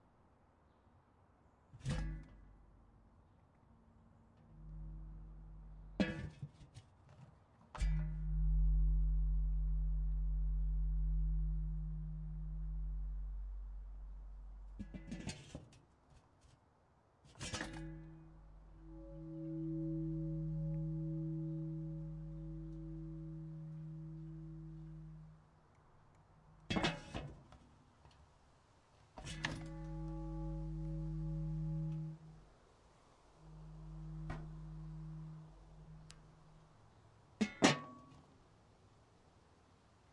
烧烤盖环
描述：烧烤盖子发出铃声。听起来像塔可钟铃声。
Tag: SFX 声音 野生